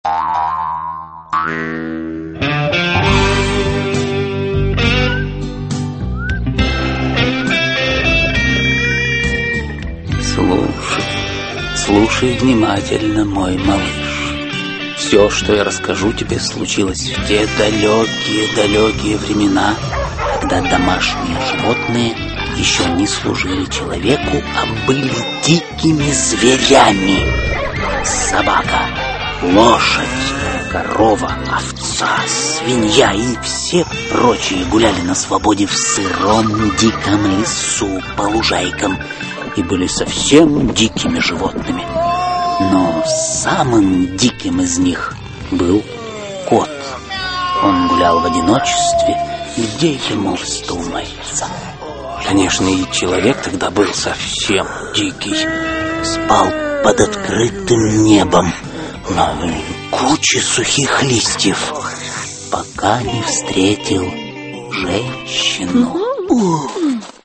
Аудиокнига Кот, который гулял сам по себе (спектакль) | Библиотека аудиокниг